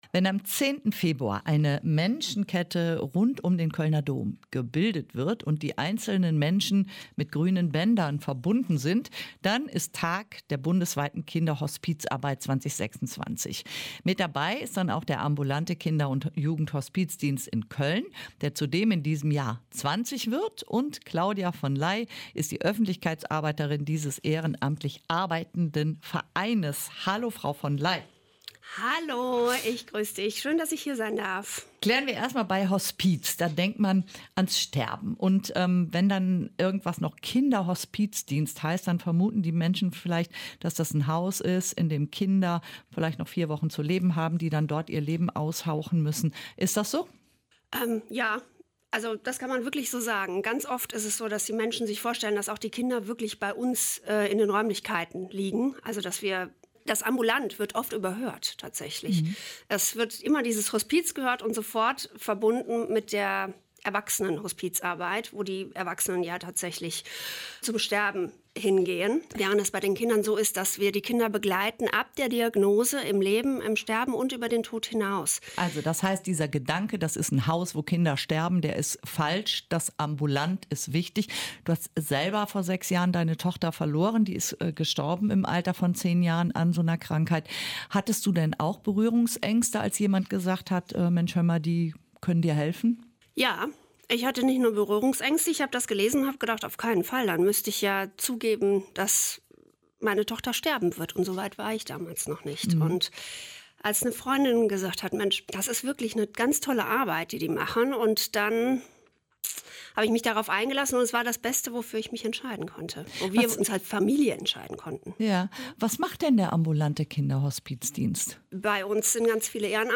Der ambulante Kinderhospiz Verein Köln Nord unterstützt und begleitet seit 20 Jahren Familien mit todkranken Kindern - und lädt zu einer Menschenkette um den Kölner Dom ein. Ein Gespräch